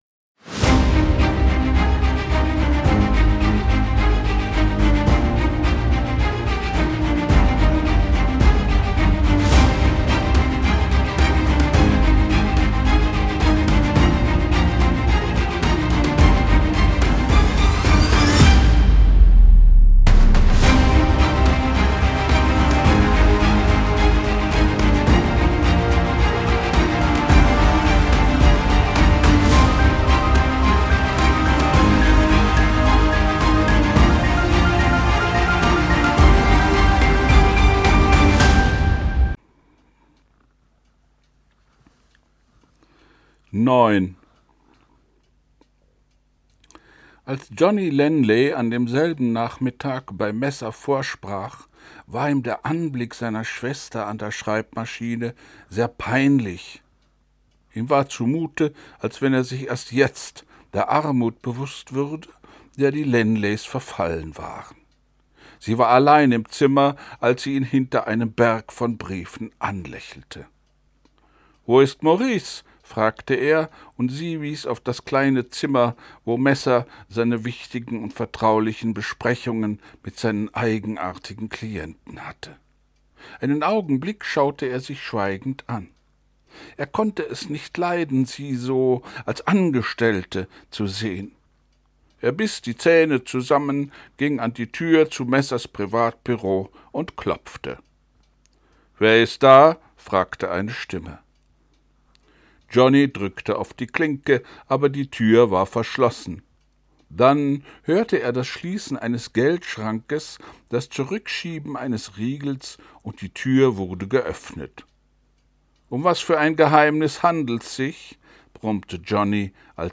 ich lese vor wallace hexer 8